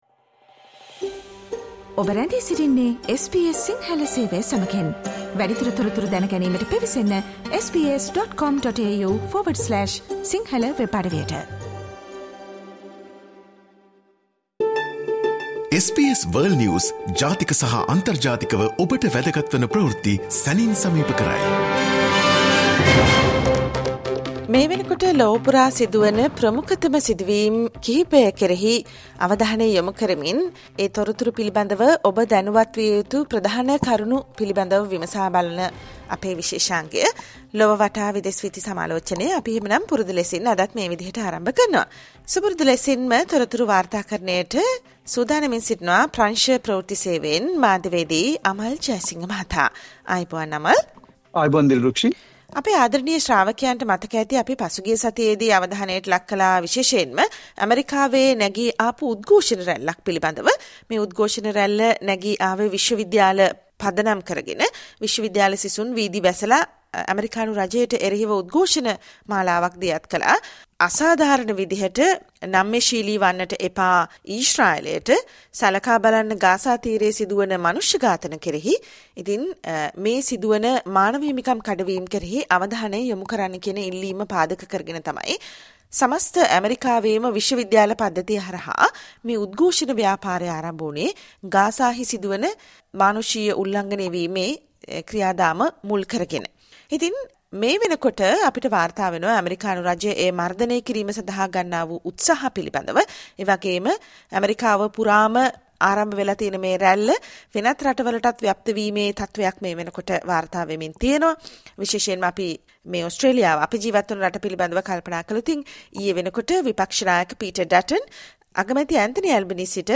listen to the world’s most prominent news highlights.
and the world news critic World's prominent news highlights in 13 minutes - listen to the SBS Sinhala Radio weekly world News wrap every Friday Share